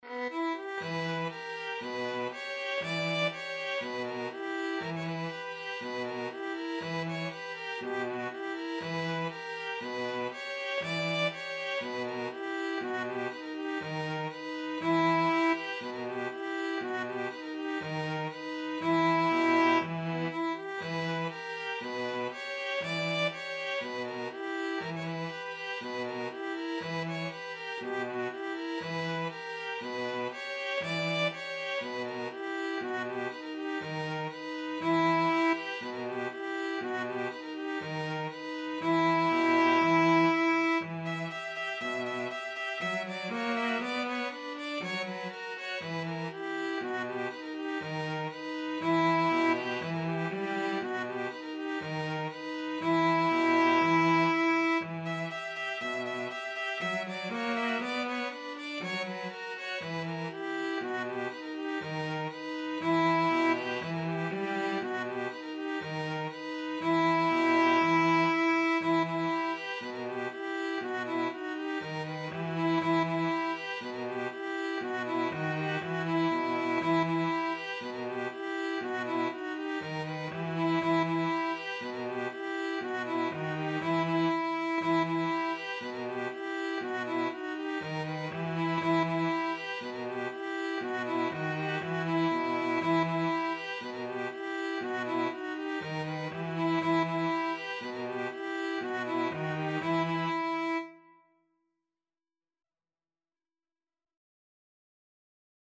Free Sheet music for String trio
ViolinViolaCello
Bulgarish refers to a popular klezmer dance form the bulgareasca.
D major (Sounding Pitch) (View more D major Music for String trio )
4/4 (View more 4/4 Music)